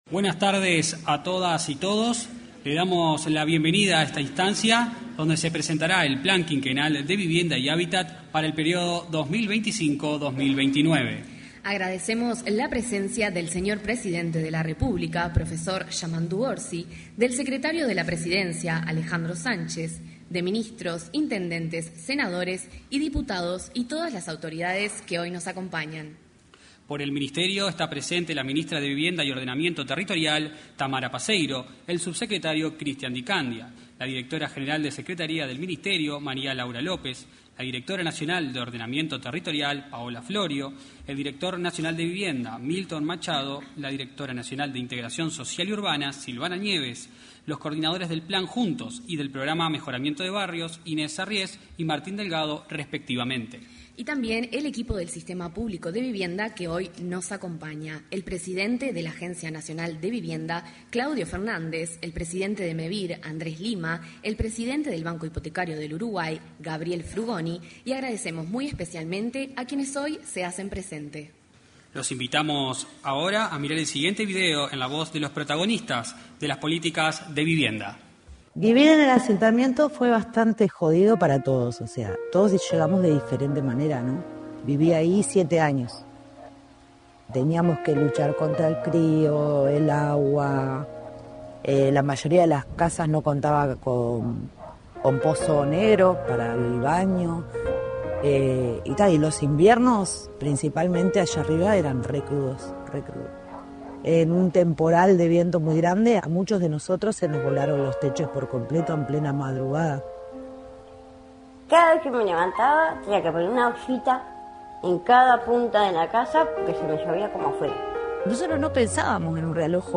En el evento, participó la ministra de Vivienda y Ordenamiento Territorial, Tamara Paseyro.